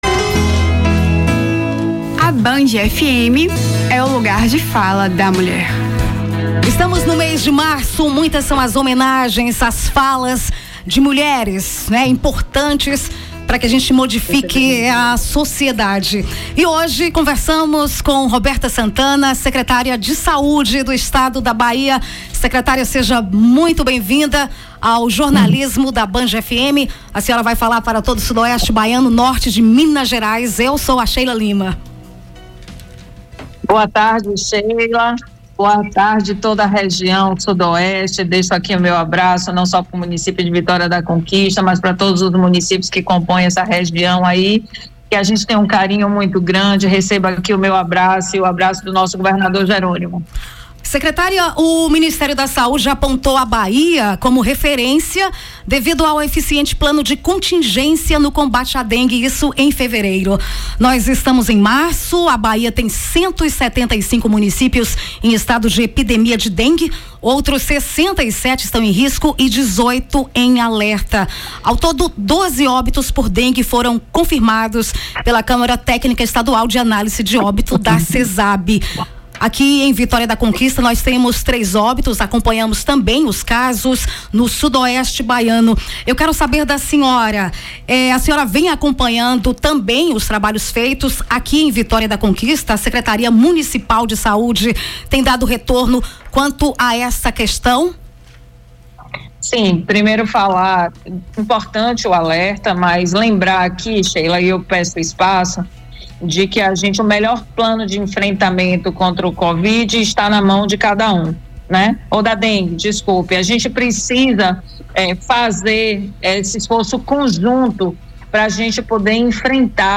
Mês da Mulher na Band FM: Entrevista com Roberta Santana
Em entrevista a Band FM, a secretária de saúde do estado comentou sobre a ação de combate a dengue com a Semana de Mobilização e Combate ao Mosquito Aedes Aegypti, que começou no último dia 11 e vai até o dia 15. Falou também sobre a campanha de vacinação para crianças e adolescentes e as unidades de pronto atendimento aqui em Vitória da Conquista.